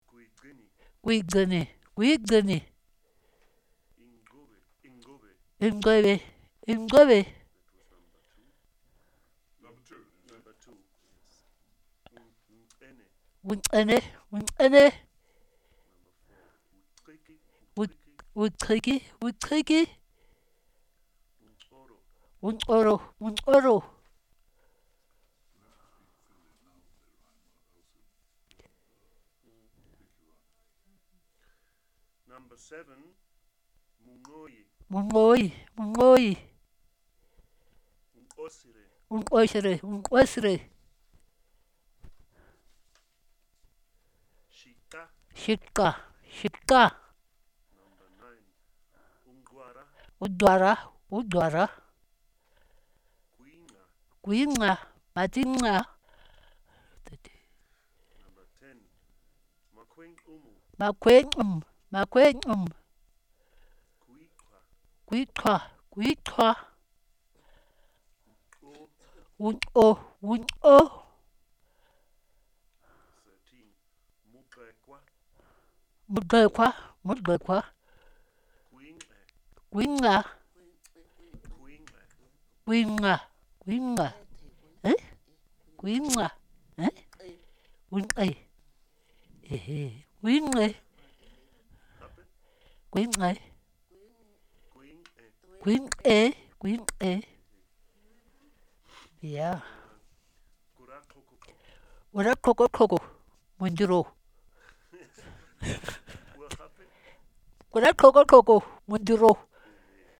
Word List Word List Entries Speaker(s) Audio Filename WAV MP3 Scanned Word List (JPG) JPG 2Scanned Word List (TIF) TIF 2Recording Details
female